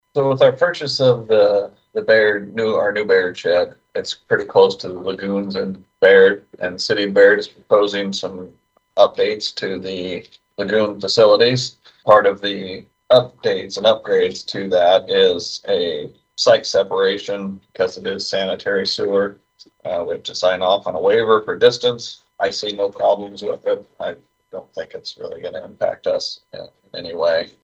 Guthrie County Engineer Josh Sebern explains…